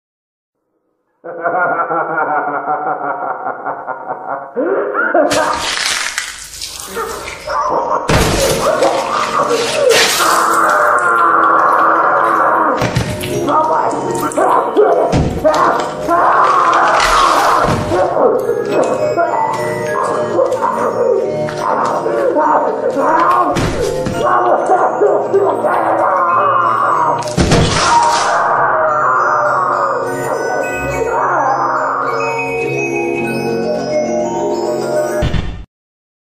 PLAY Spring lock Meme Sound Effect for Soundboard